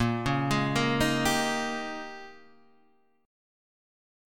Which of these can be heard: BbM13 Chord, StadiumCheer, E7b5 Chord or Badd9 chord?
BbM13 Chord